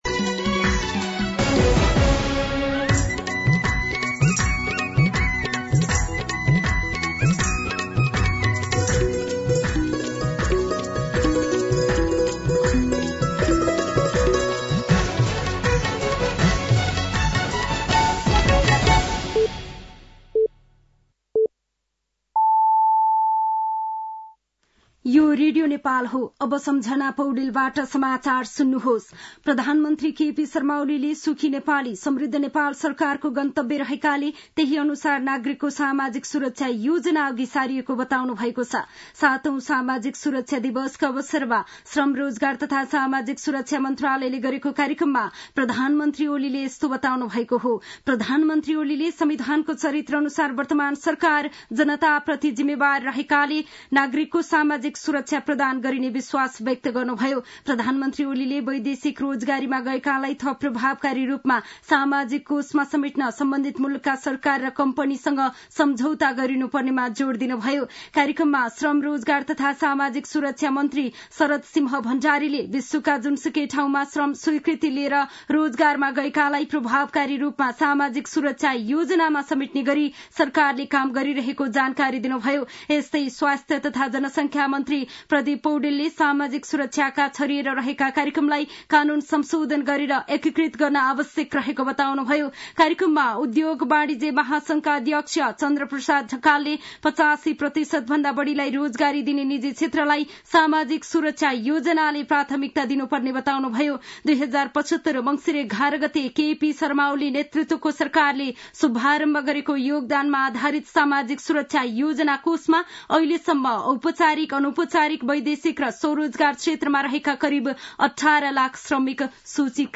साँझ ५ बजेको नेपाली समाचार : १२ मंसिर , २०८१
5-PM-Nepali-News-8-11.mp3